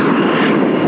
jet2.au